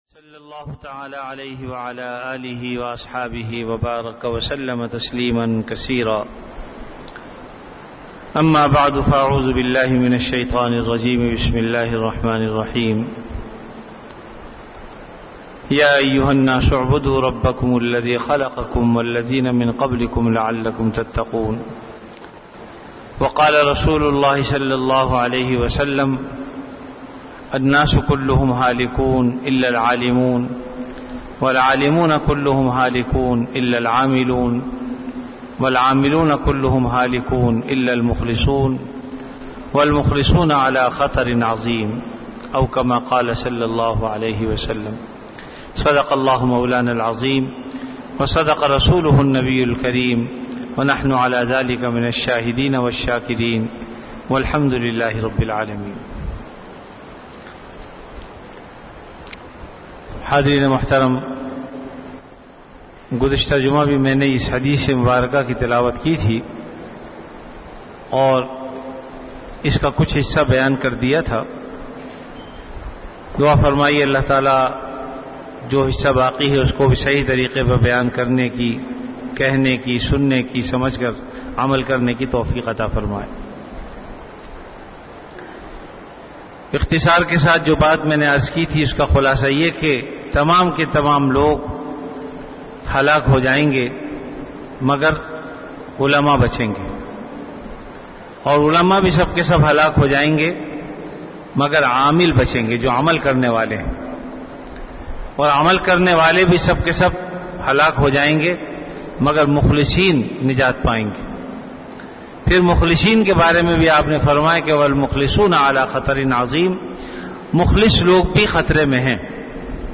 Bayanat · Jamia Masjid Bait-ul-Mukkaram, Karachi